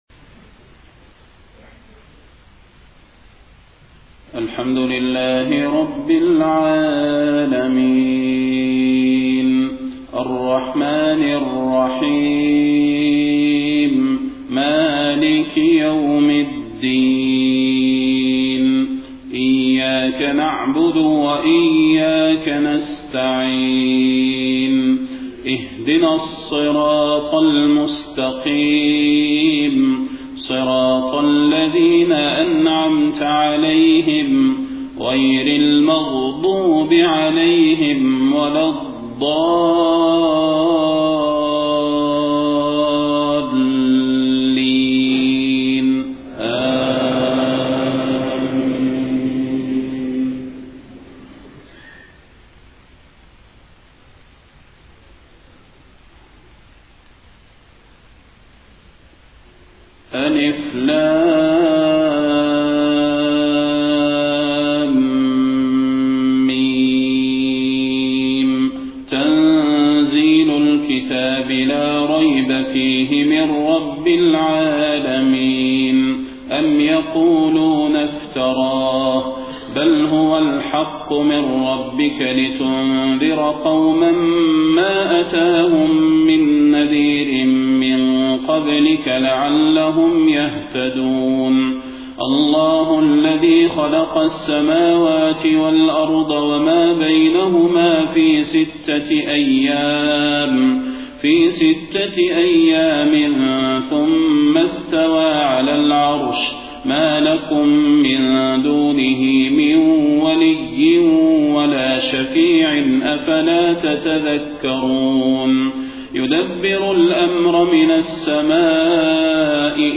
صلاة الفجر 28 صفر 1431هـ سورتي السجدة و الإنسان > 1431 🕌 > الفروض - تلاوات الحرمين